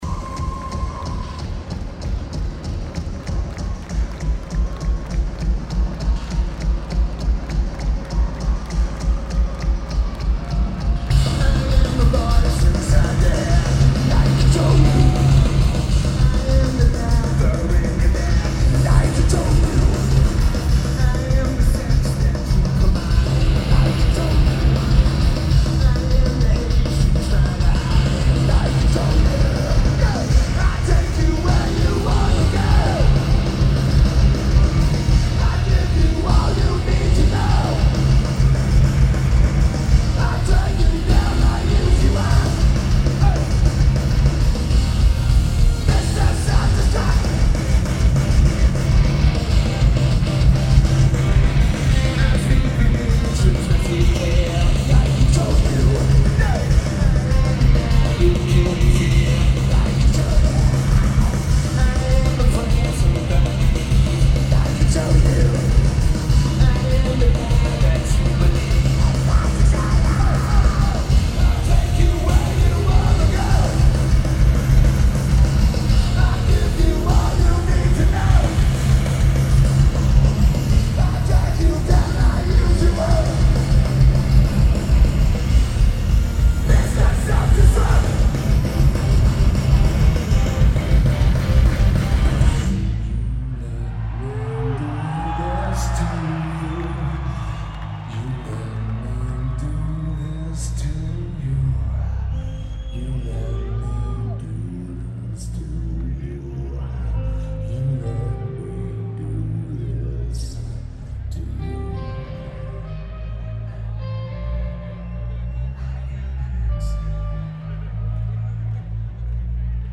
Verizon Wireless Music Center